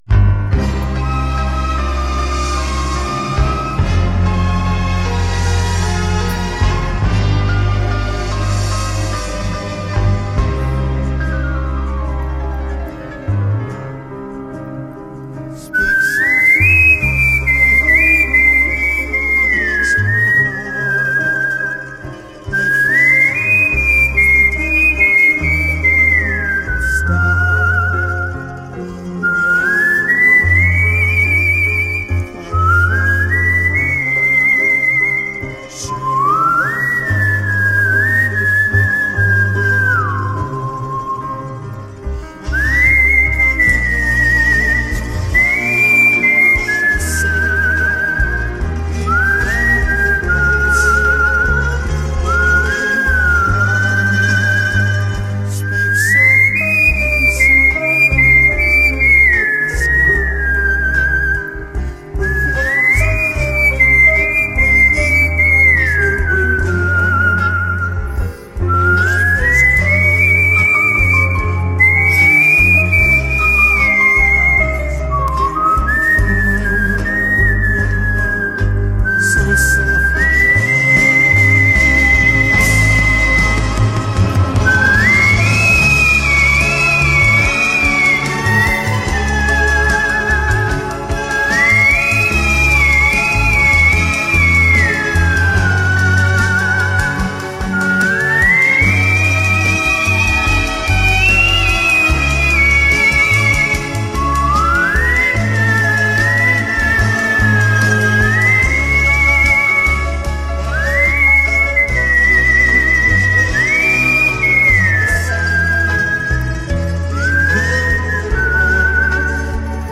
• 趁去厕所的功夫听了大作，圆润 饱满 流畅 华丽，很传神， 很给力，music to my ears.
本想挑刺儿，可是可是毫无瑕疵啊，除了最后个别高音略显吃力。
• 中间那段无词的间奏与歌唱部分吹法有所区别，连吹吹得很美。